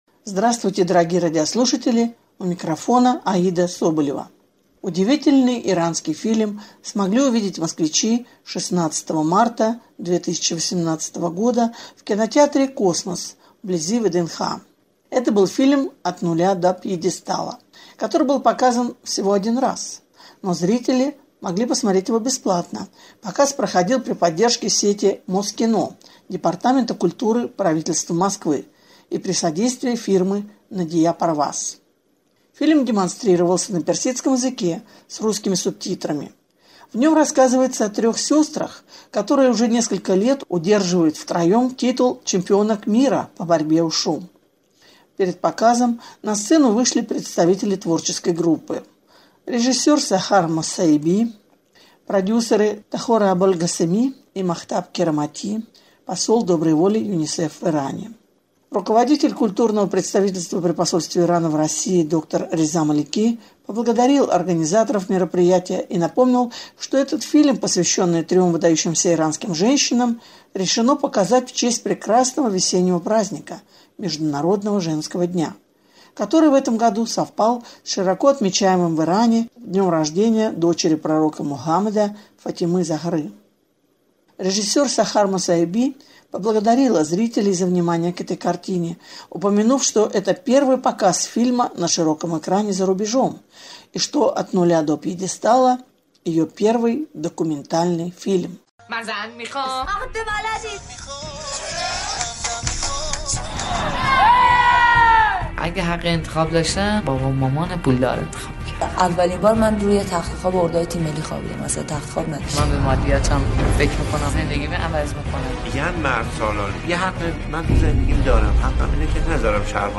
(фрагмент фонограммы фильма) То, что фильм документальный, зритель понимает далеко не сразу – фильм снят в стилистике игрового кино, а технический уровень съемок и звука соответствует голливудским ст